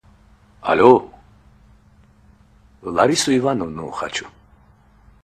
Рингтоны » Саундтреки